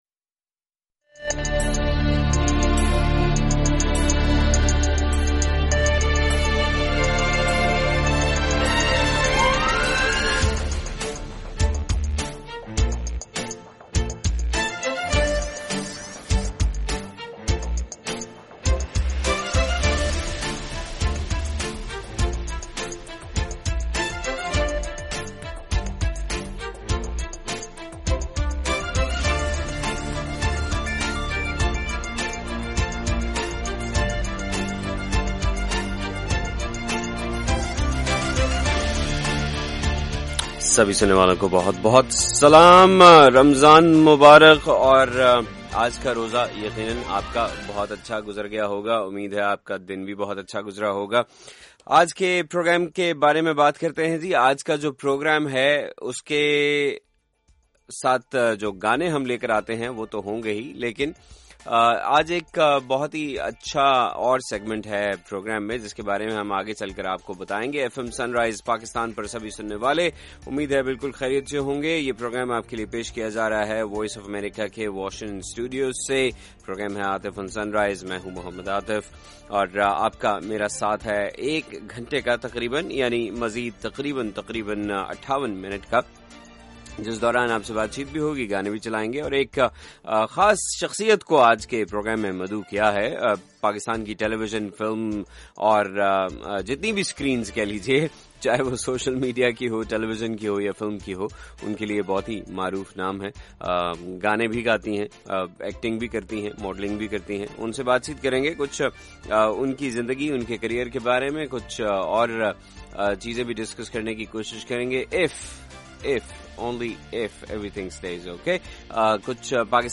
منگل 30 جون کے پروگرام میں سنیے پاکستان فلم اور ٹی وی کی جگمگاتی اداکارہ مہوش حیات سے